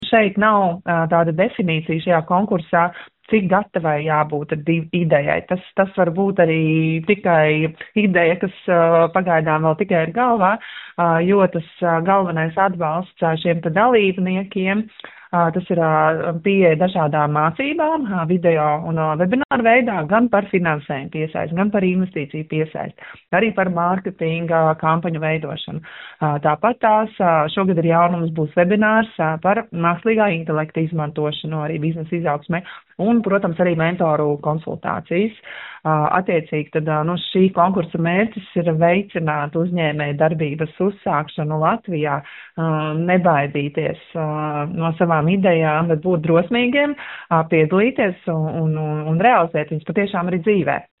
Latvijas Investīciju un attīstības aģentūras direktore Ieva Jāgere